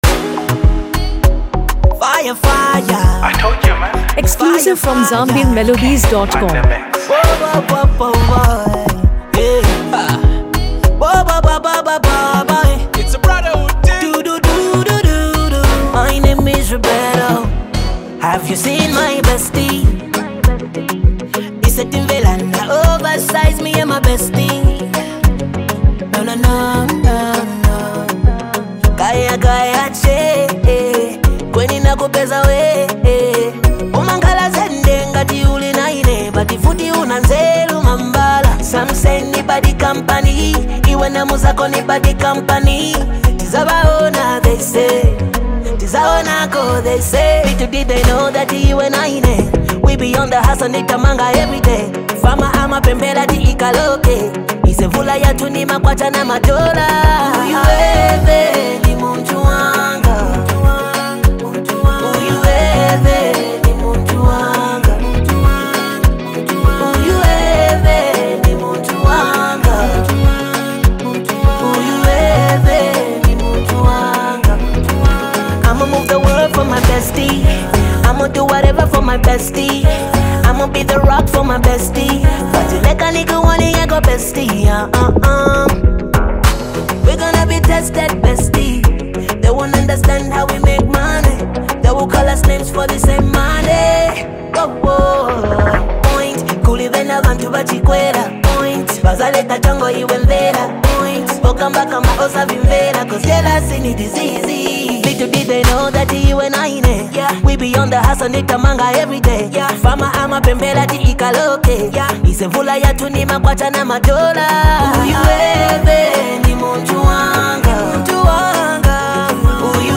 soft instrumentals
this track is both uplifting and emotionally touching.
” for its relatable lyrics and soothing sound.